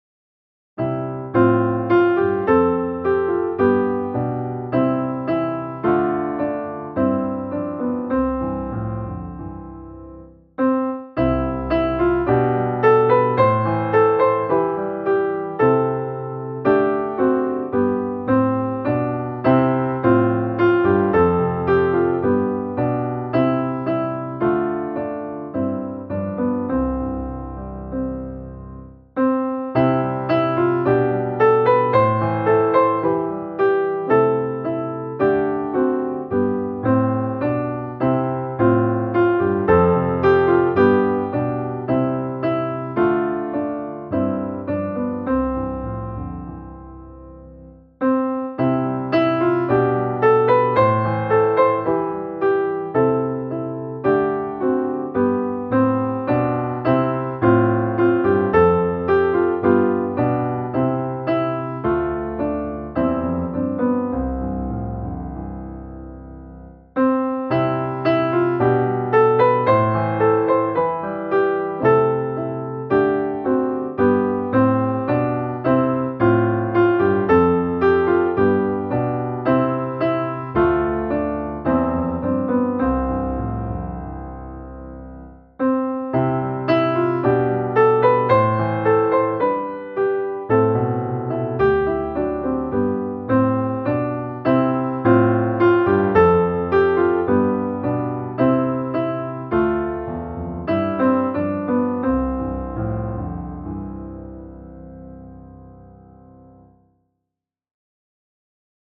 Du öppnar, o evige Fader - musikbakgrund
Musikbakgrund Psalm